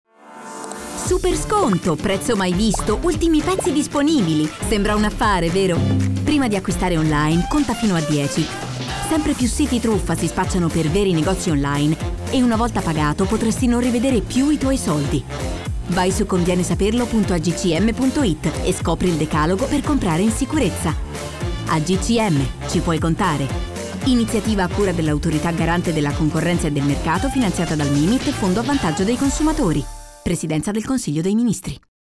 Lo spot radio